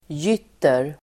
Uttal: [j'yt:er]